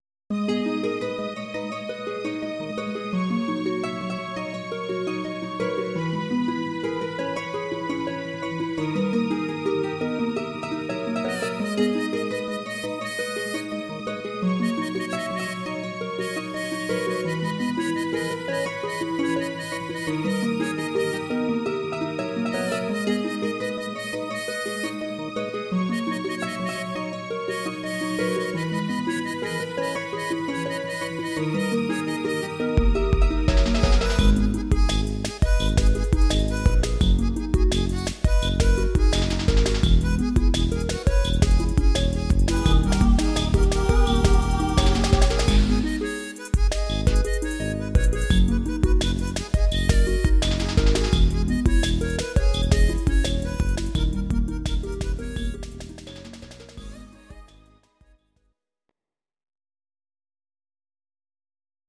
Audio Recordings based on Midi-files
Ital/French/Span, 1990s